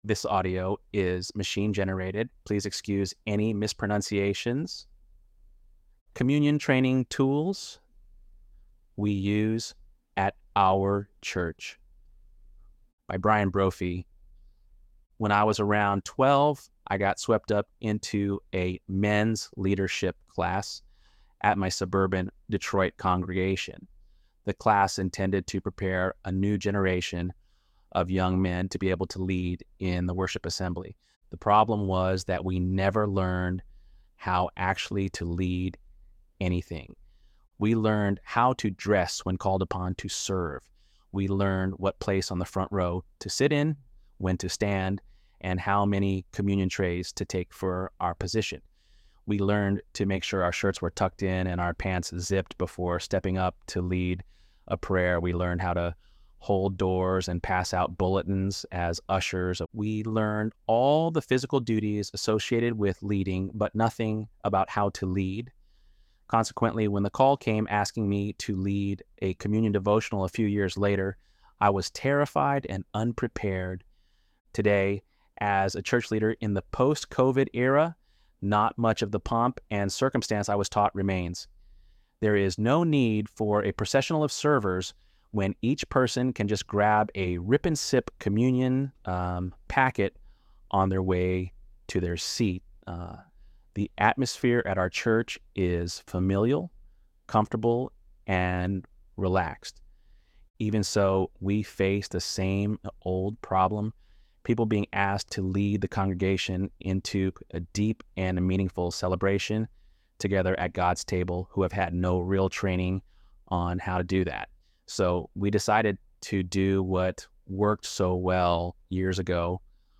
ElevenLabs_7.26_Tools.mp3